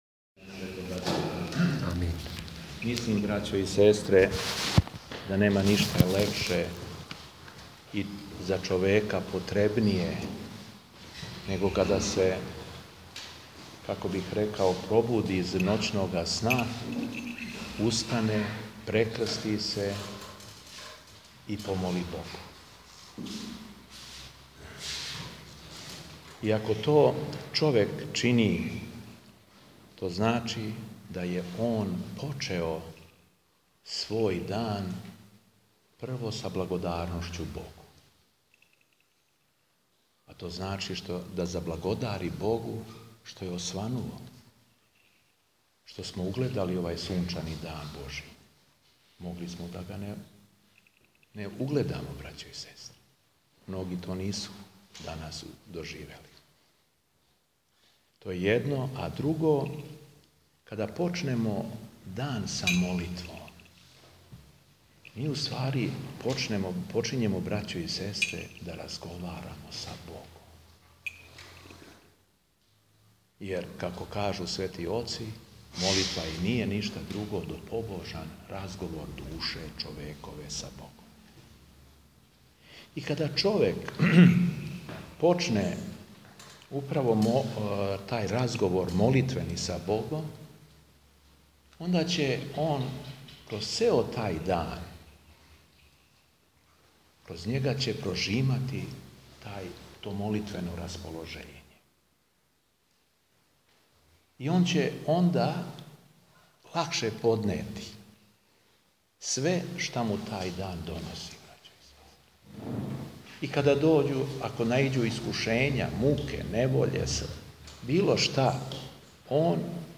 ЛИТУРГИЈА У ХРАМУ СВ. ДИМИТРИЈА У СУШИЦИ - Епархија Шумадијска
Беседа Епископа шумадијског Г. Јована